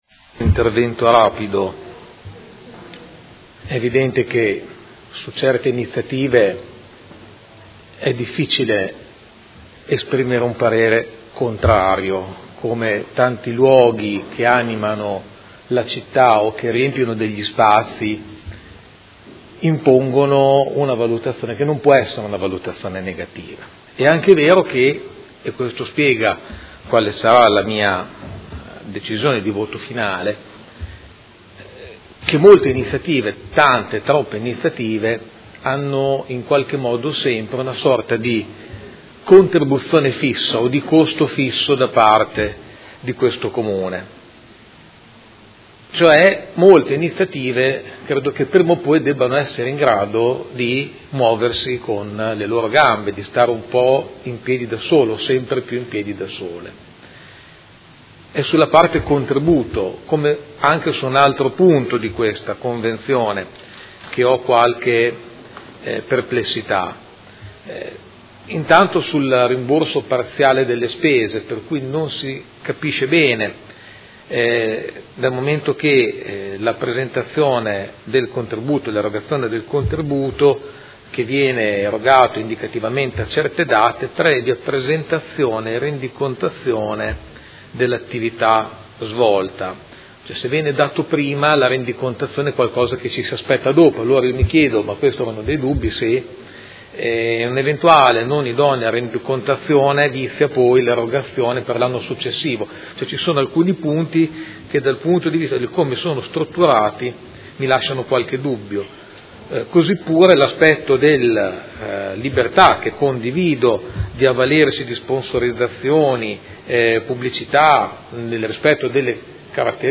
Seduta del 28/03/2019. Dichiarazioni di voto su proposta di deliberazione: Associazione Circuito Cinema – Applicazione del codice del terzo settore e nuova convenzione